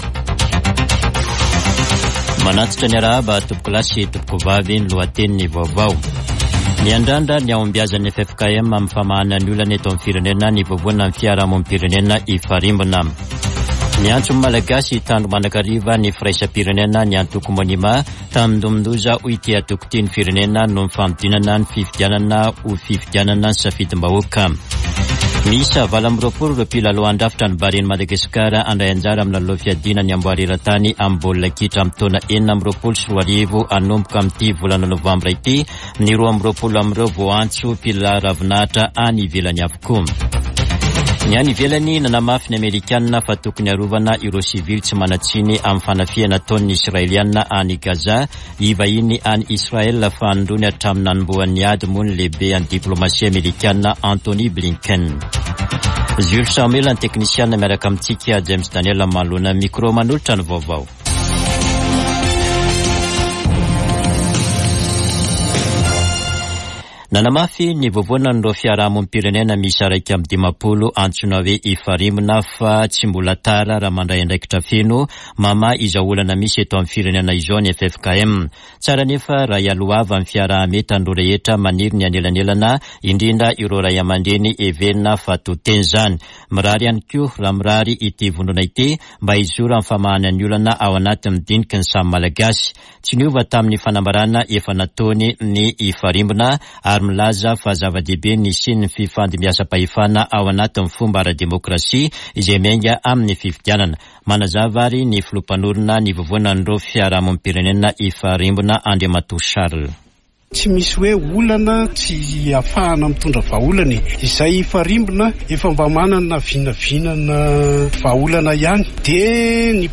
[Vaovao antoandro] Zoma 3 nôvambra 2023